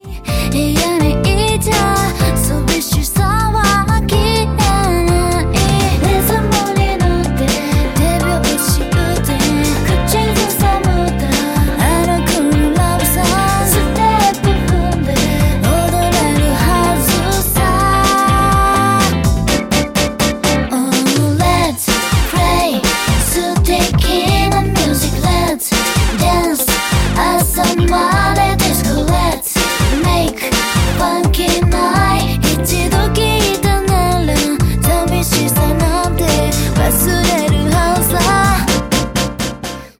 ジャンル(スタイル) JAPANESE POP / CITY POP